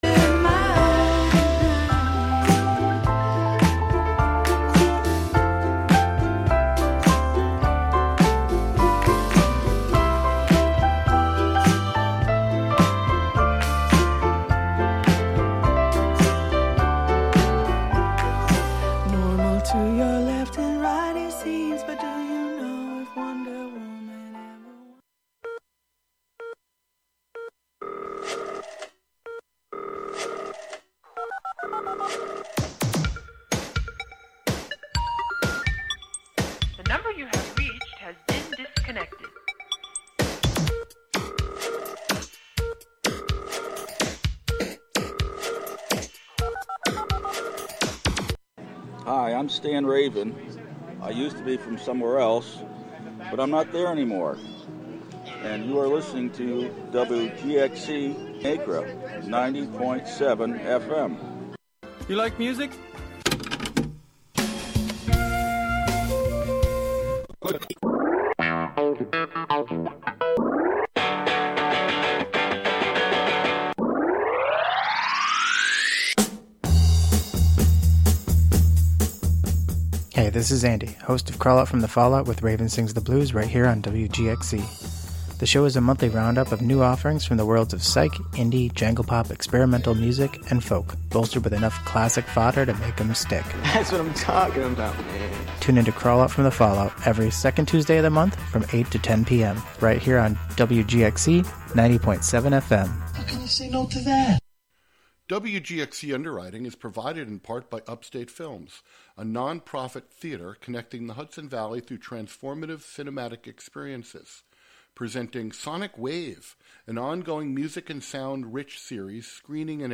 This month we listen to singing that links the voice and body to technology. There is also an improvised performance in which the presenter sings along with the EM fields generated by the devices on his desk.